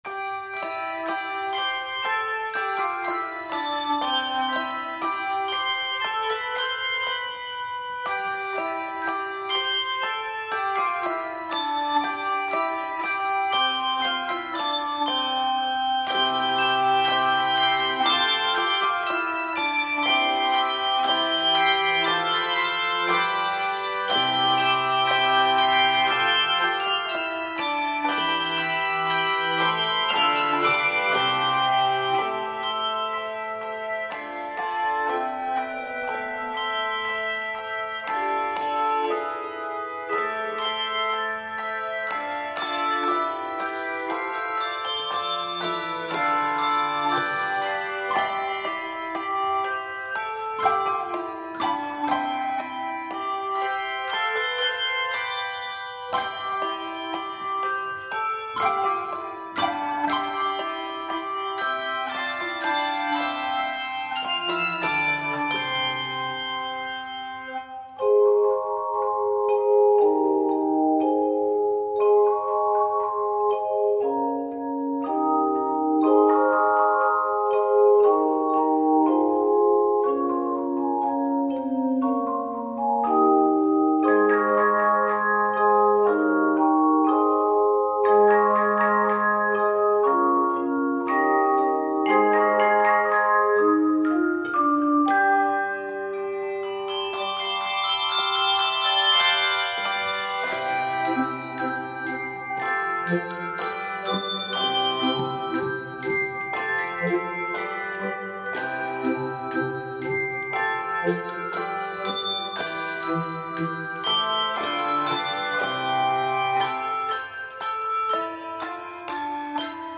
lilting melody and extended techniques